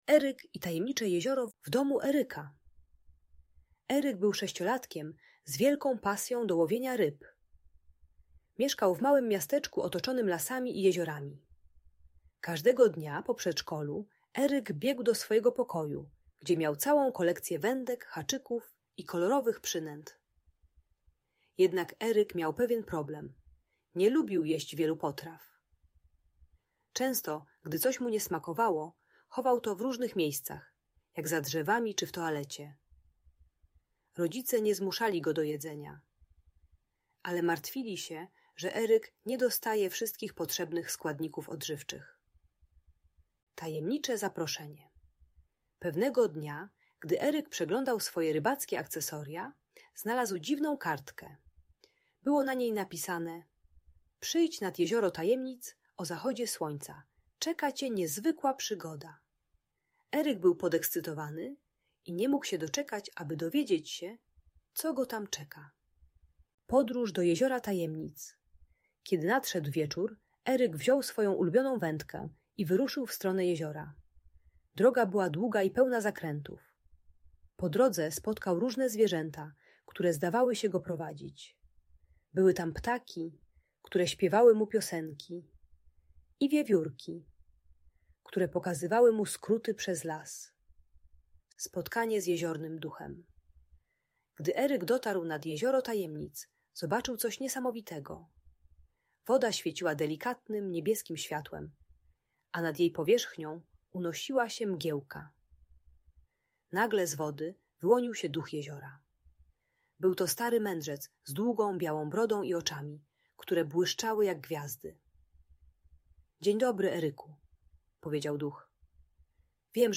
Eryk i Tajemnicze Jezioro - Problemy z jedzeniem | Audiobajka